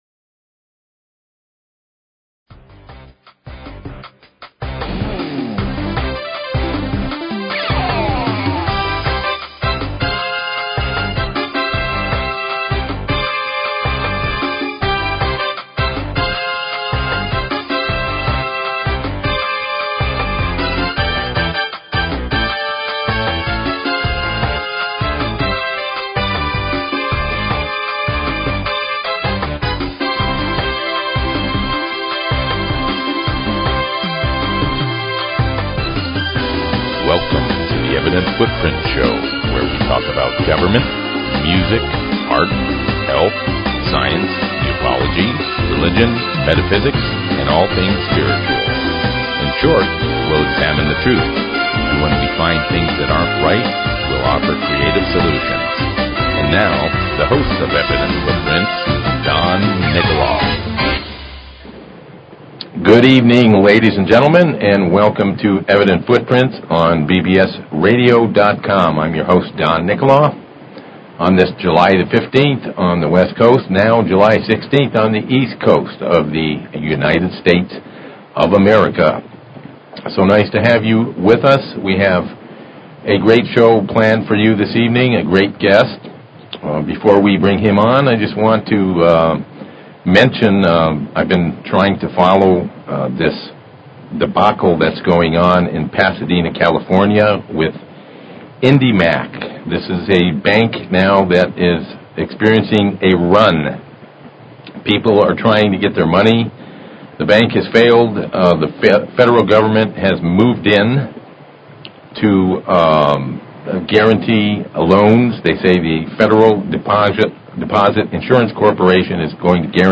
Talk Show Episode, Audio Podcast, Evident_Footprints and Courtesy of BBS Radio on , show guests , about , categorized as
Civil Rights/Antitrust Attorney